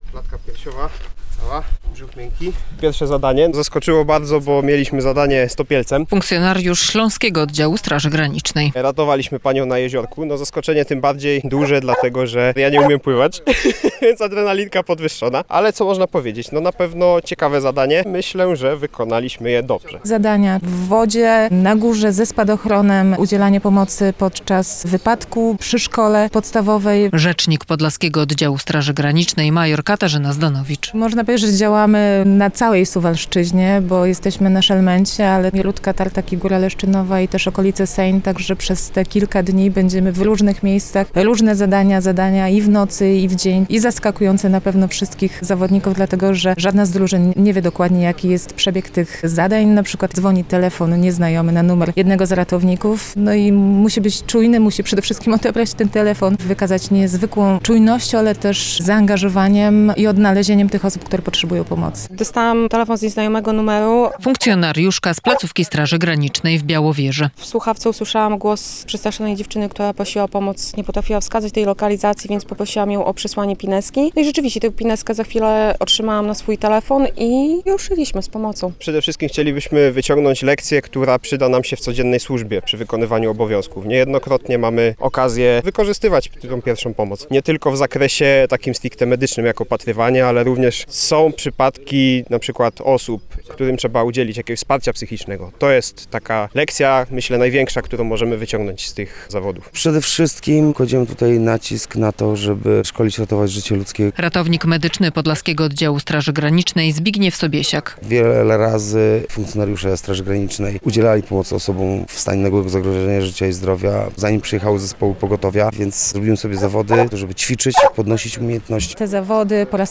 Polskie Radio Białystok